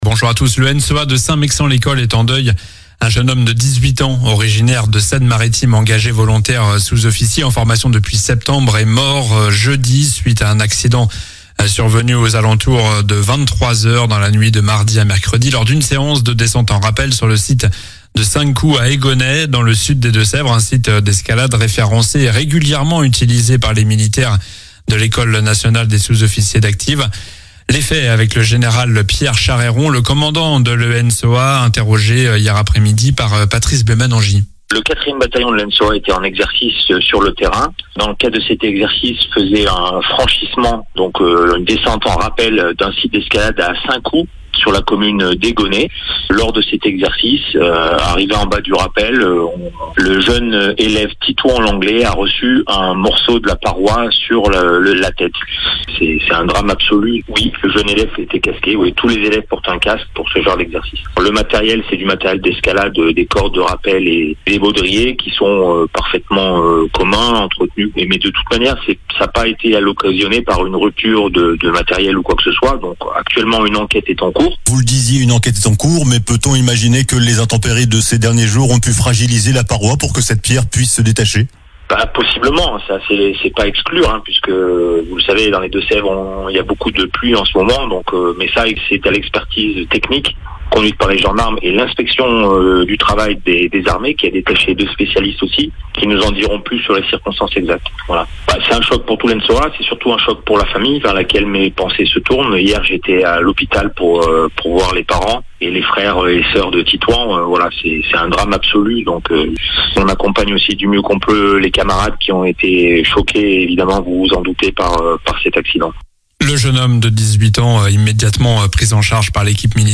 Journal du samedi 14 février